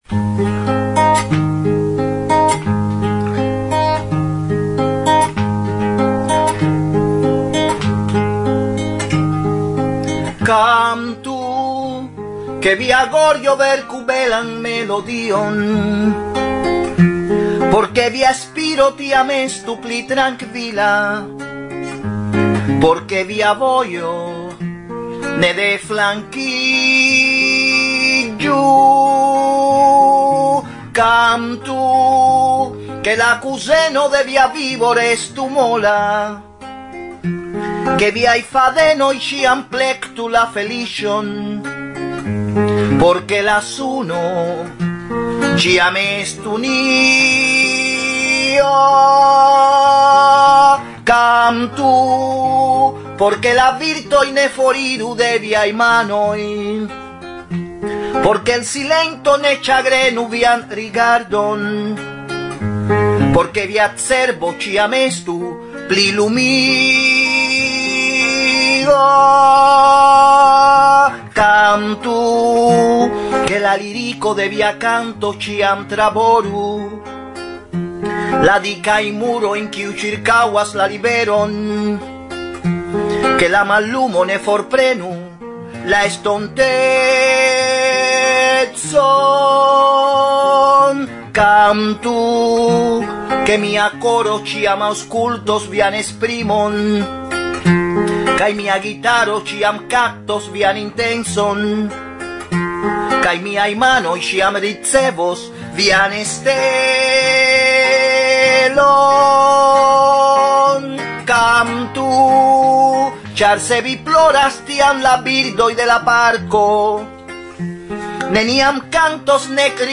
Fragmentoj de la neoficiala koncerto
SES 2011, Nitra – Slovakio